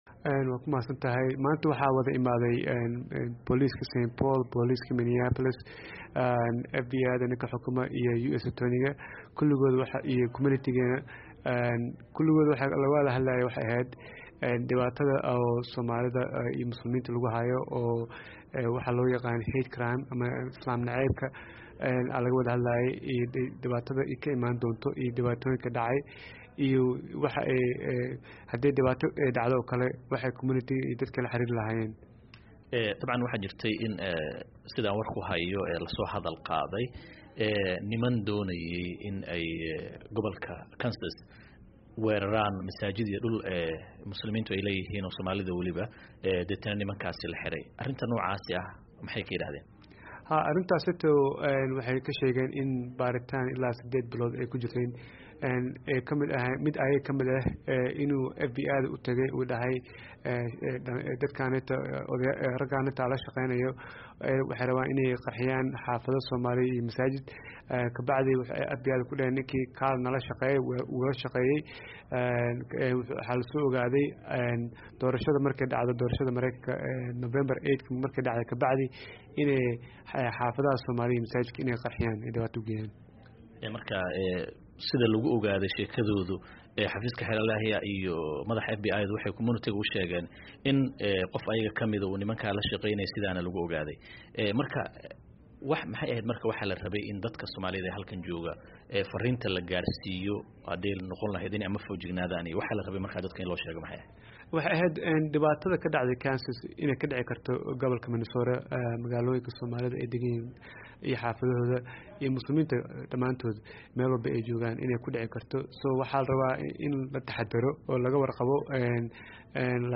wareysiga